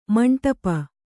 ♪ maṇṭapa